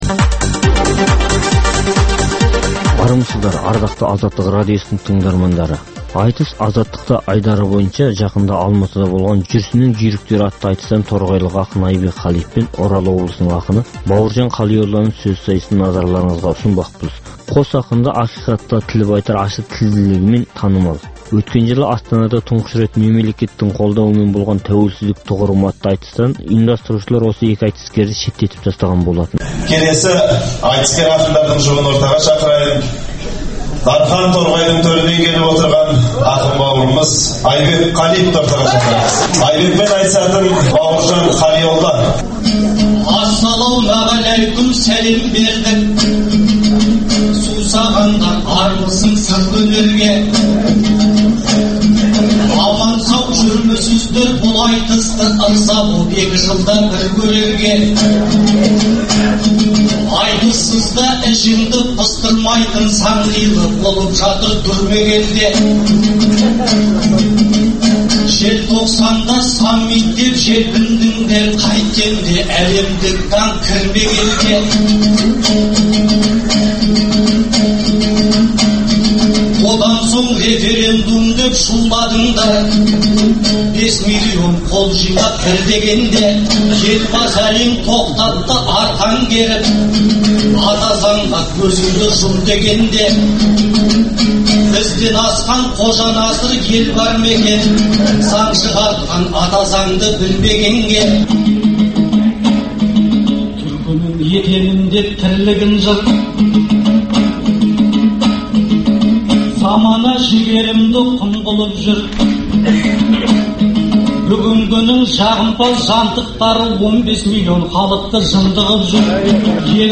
Айтыс - Азаттықта